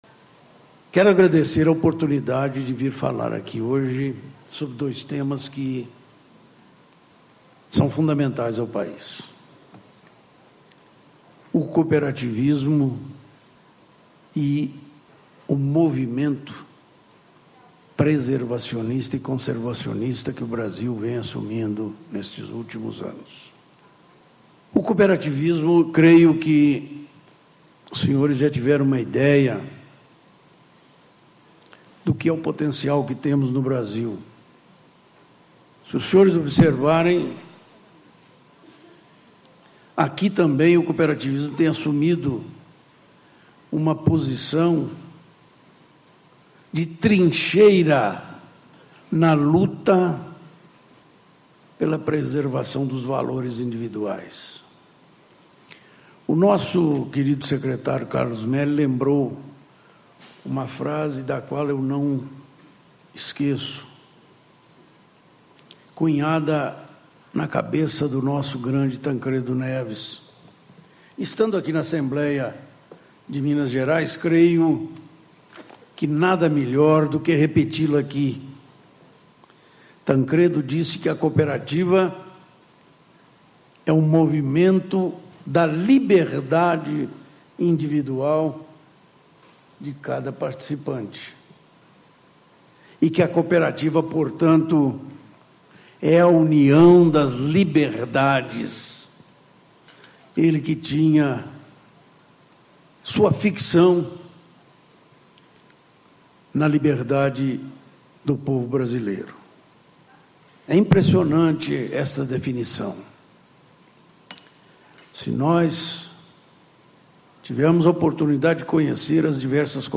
Alysson Paolinelli, Ex-Ministro da Agricultura e Presidente Executivo da Associação Brasileira dos Produtores de Milho - Abramilho. Painel: Sustentabilidade e negócios
Ciclo de Debates Cooperar 2012 - Ano Internacional das Cooperativas